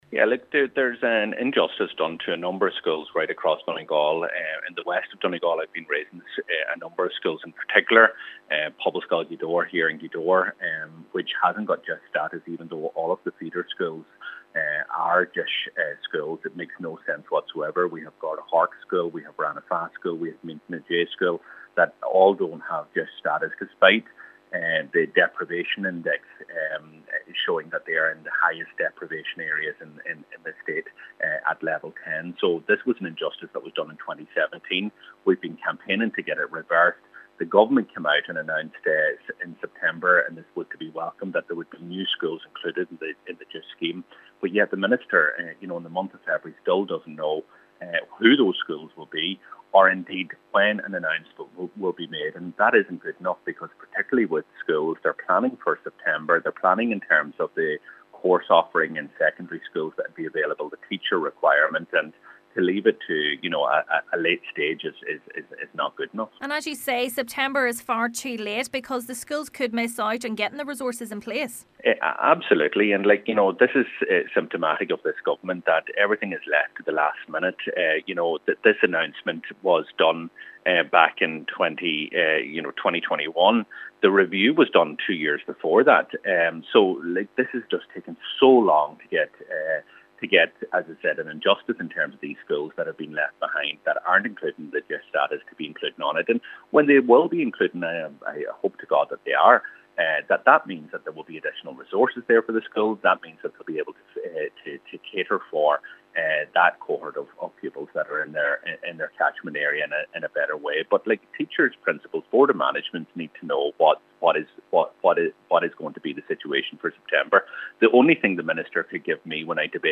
Deputy Doherty says for schools this is too late: